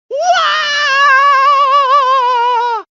Patchwork Donkey Screaming Botão de Som
Sound Effects Soundboard33 views